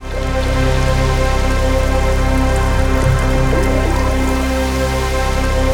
DM PAD1-04.wav